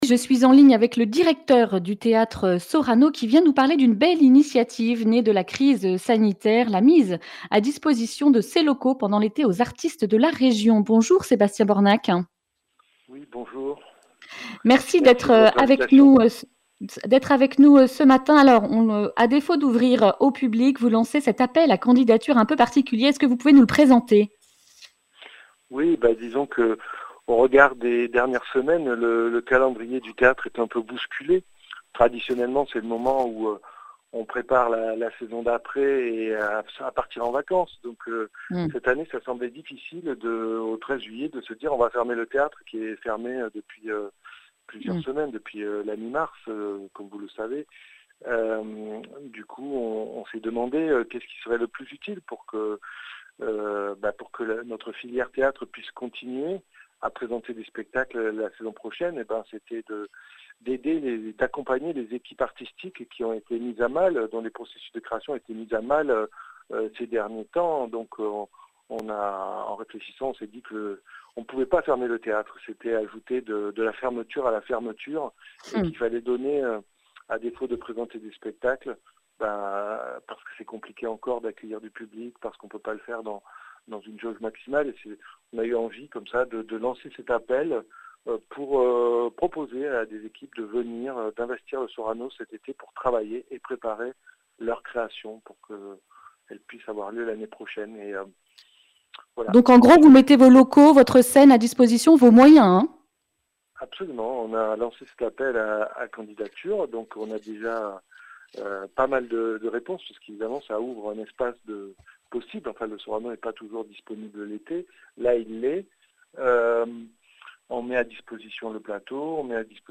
mardi 9 juin 2020 Le grand entretien Durée 11 min